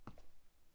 Add footstep sound effect and animation
Step.wav